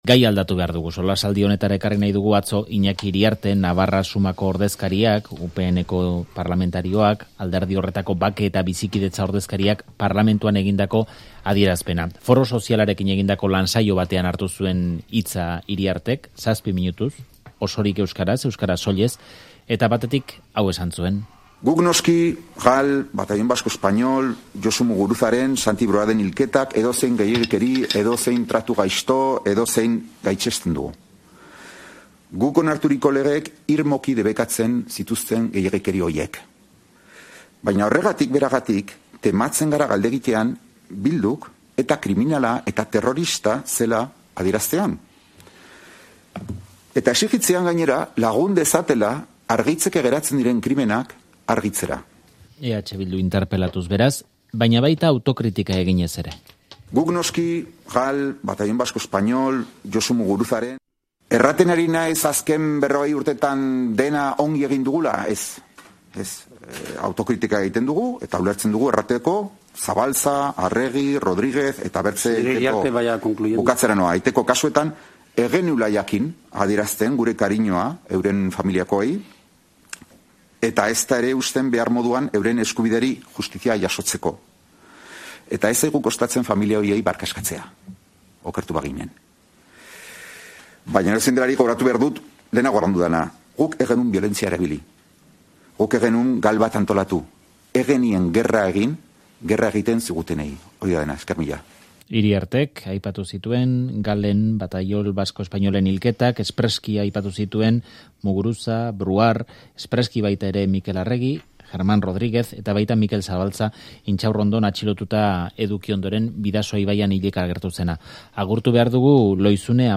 elkarrizketatu dugu Faktorian